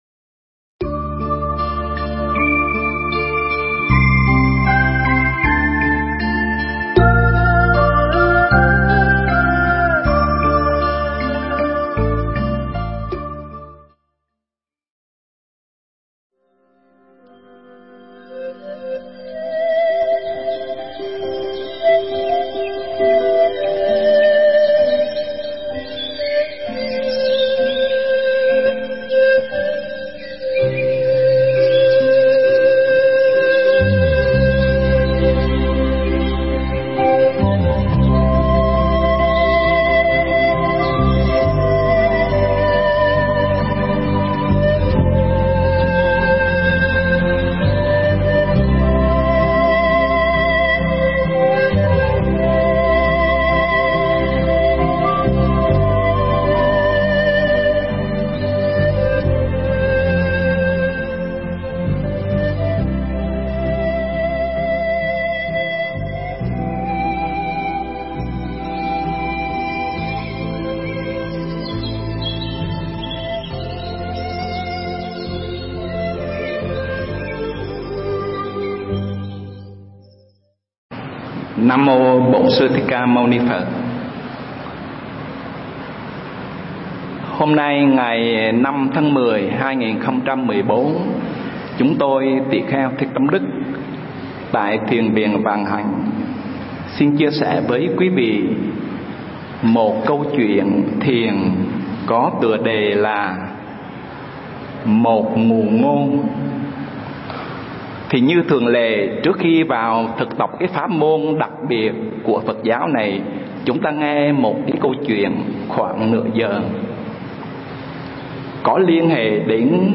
Nghe Mp3 thuyết pháp Một Dụ Ngôn